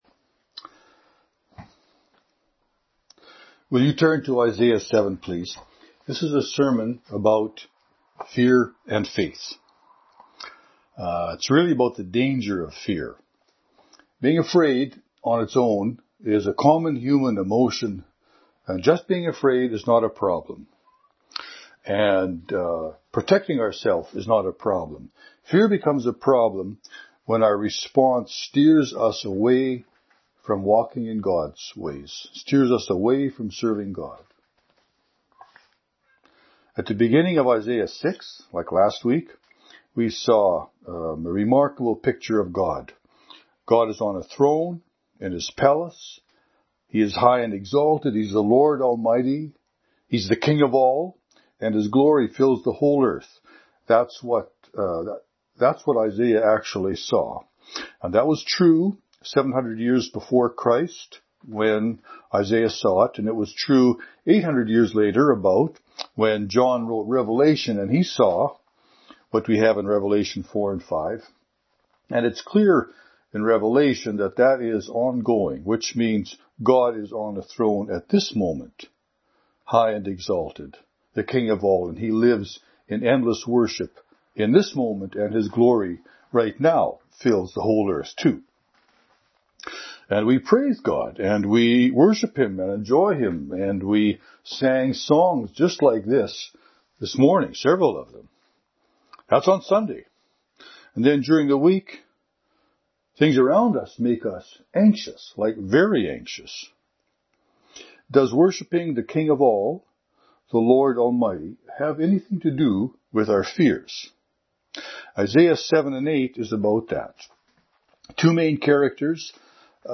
This sermon is about the danger of fear.